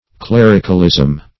Clericalism \Cler"ic*al*ism\, n.